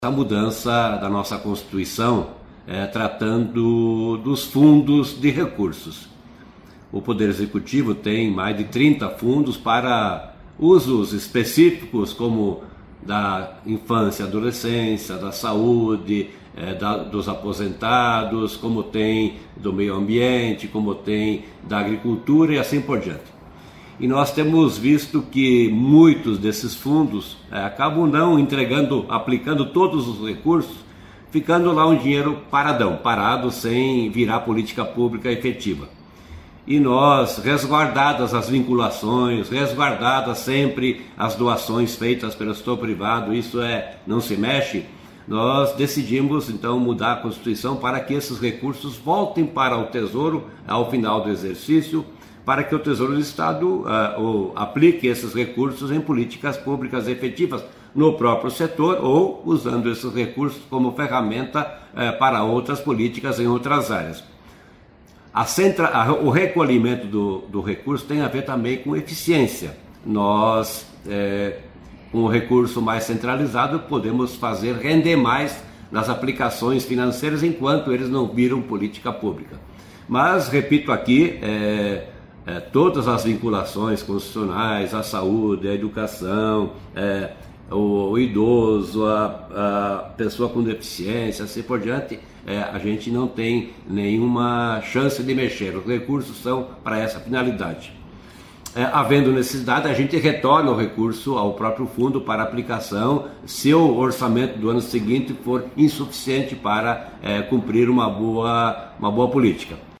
Sonora do secretário Estadual da Fazenda, Norberto Ortigara, sobre a PEC que centraliza saldos de fundos do Executivo em finais de ano